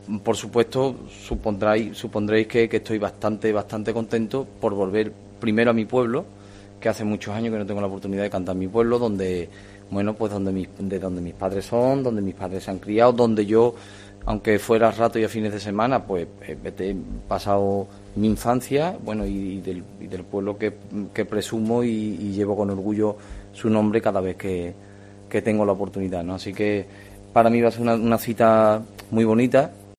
Arcángel, cantaor